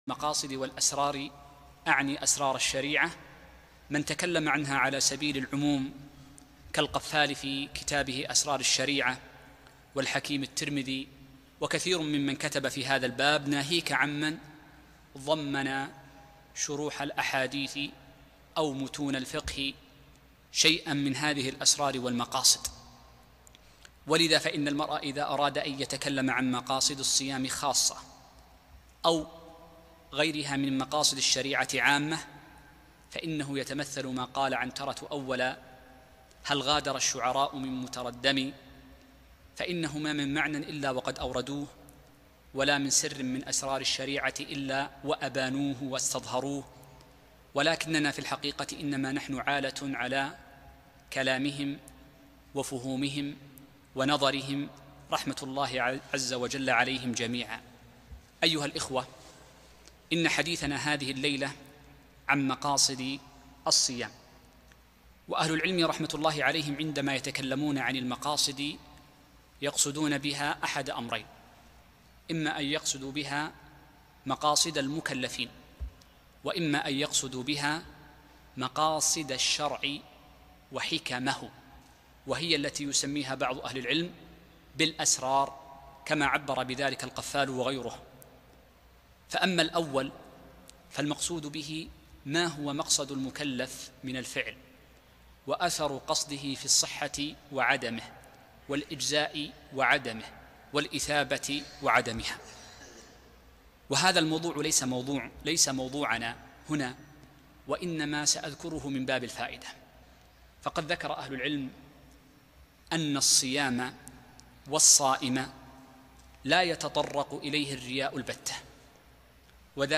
مقاصد الصوم - محاضرة مفيدة جدا للاستفادة من رمضان